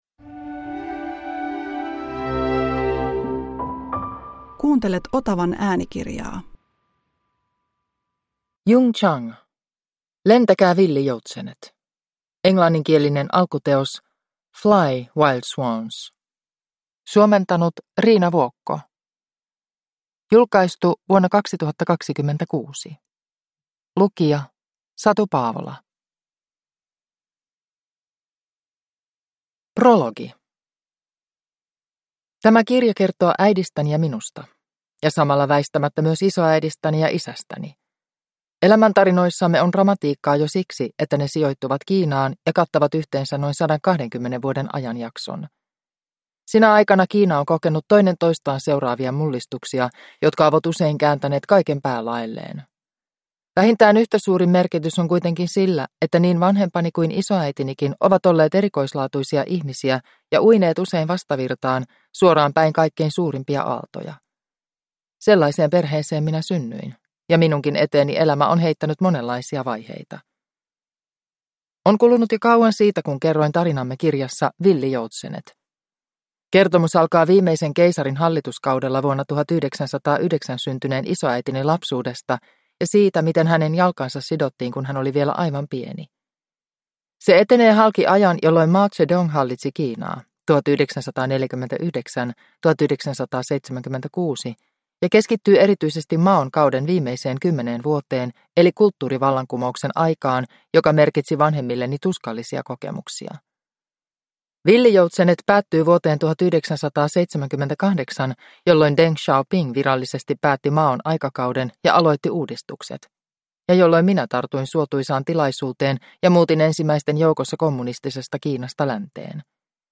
Lentäkää villijoutsenet – Ljudbok